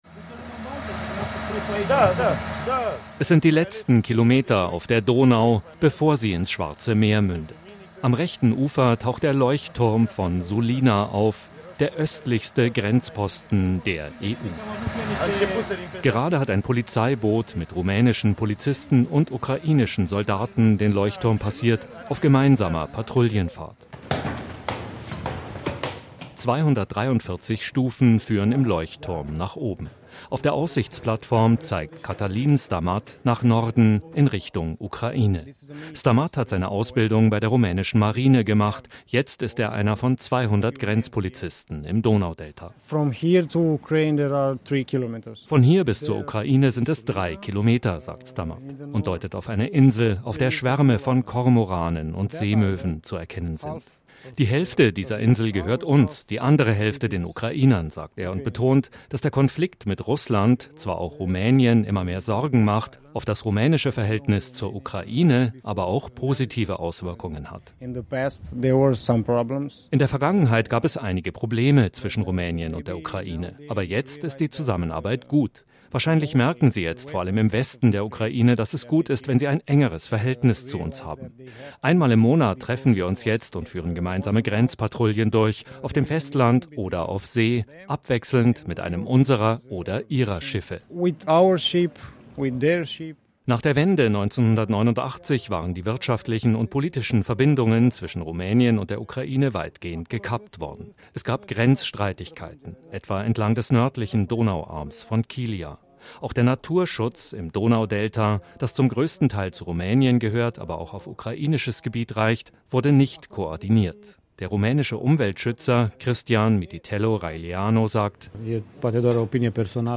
Oestlichster-Grenzposten-der-EU-der-Leuchtturm-von-Sulina-im-Donaudelta.mp3